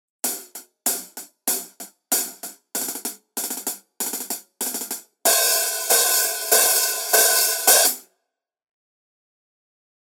Тарелки серии Custom обладают широким частотным диапазоном, теплым плотным звуком и выдающейся музыкальностью.
Masterwork 13 Custom Hats sample
Custom-Hihat-13.mp3